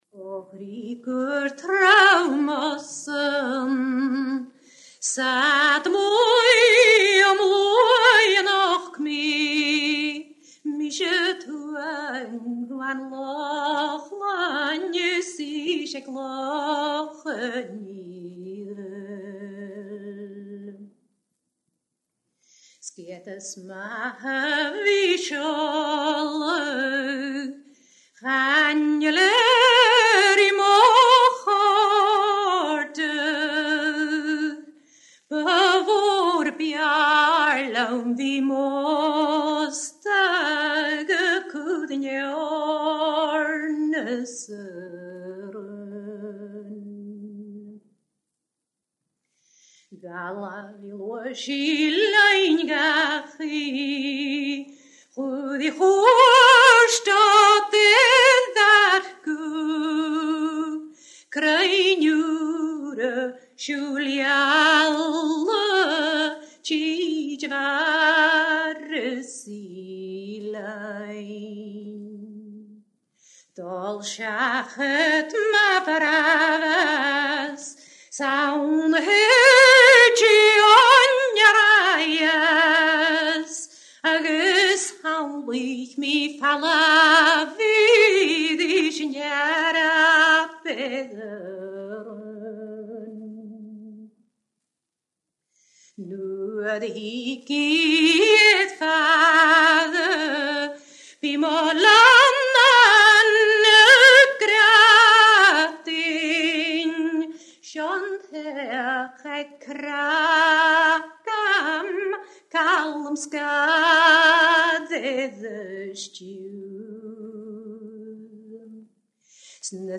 Singer 1914 – 2000
Traditional singer
These two tracks are from the 45 rpm record Gaelfonn GLA.2502 [6113 and 6114].